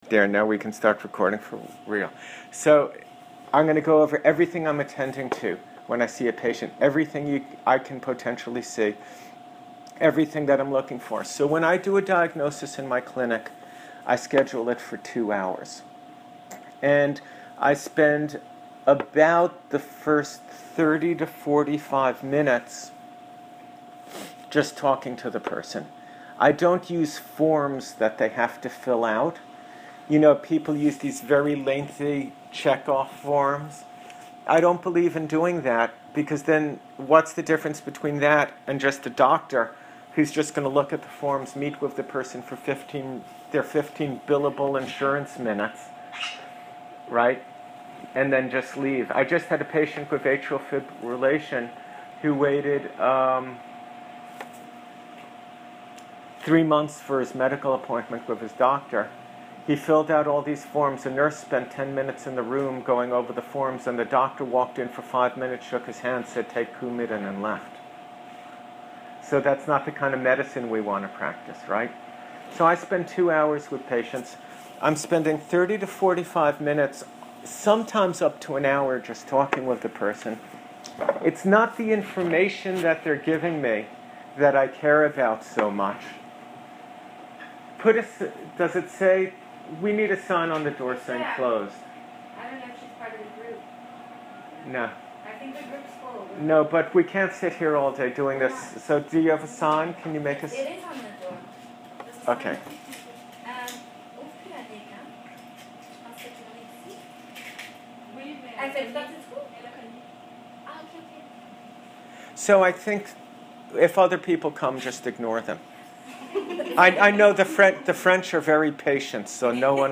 Audio Teachings